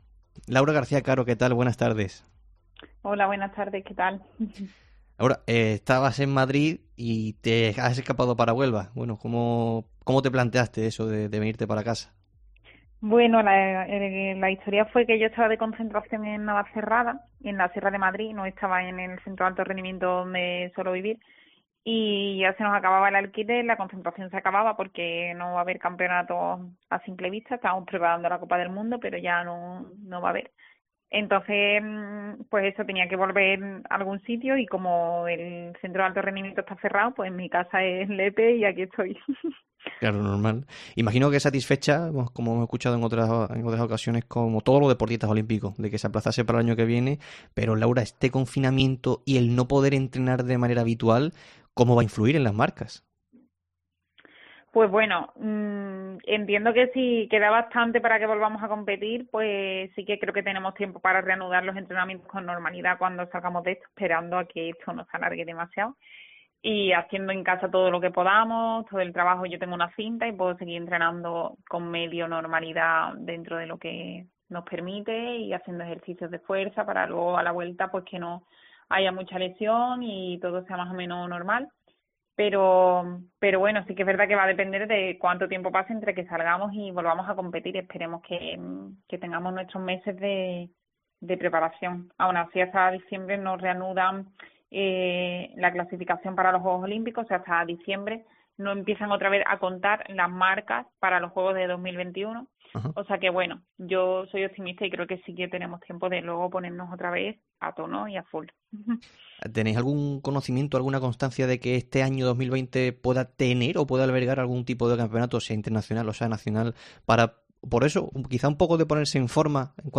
En el tiempo local de Deportes COPE hablamos con la atleta Laura García-Caro sobre cómo se plantea este 2020 y de los JJOO de Tokio pospuestos para el 2021